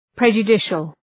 Προφορά
{,predʒə’dıʃəl}
prejudicial.mp3